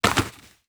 Dirt footsteps 2.wav